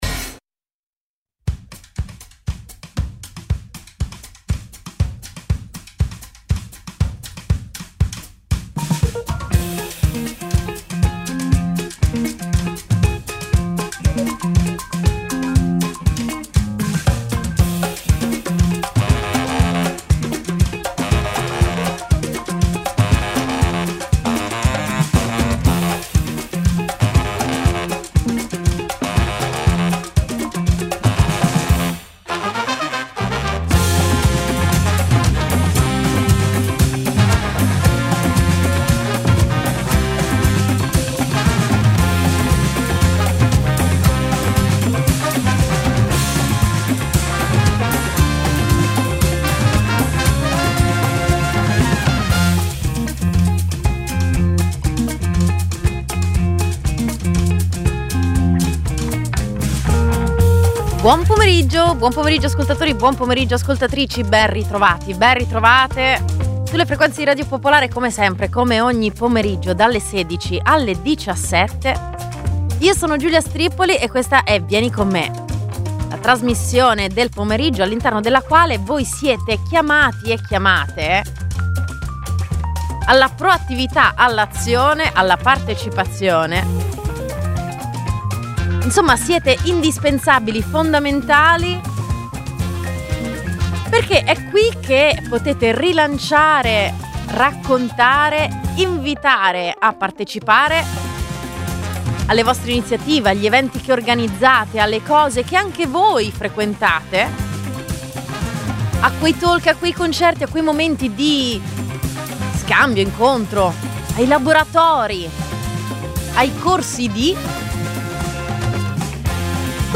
Uno spazio radiofonico per incontrarsi nella vita.